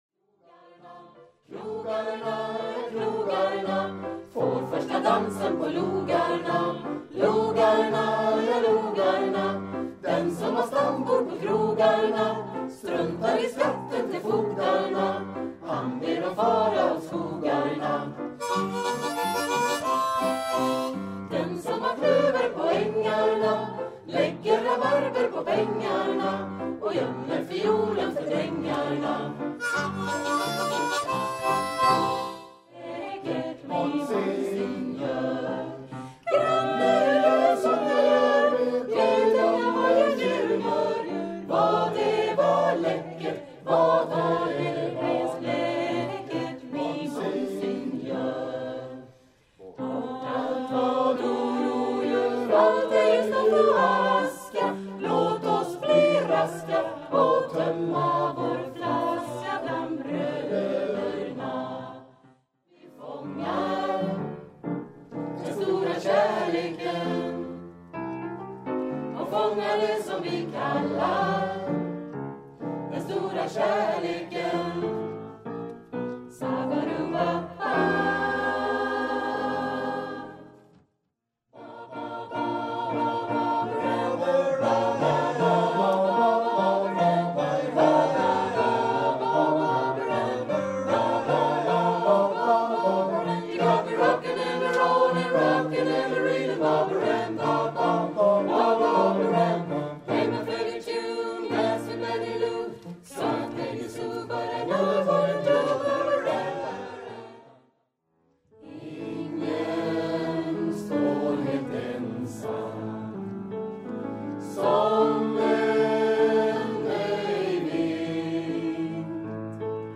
Söndagen den 23 november 2008 var Jättafjätskören ute på sin första turné. Det blev tre framträdanden på Götene Äldrecenter och kören framförde ett 10-tal sånger.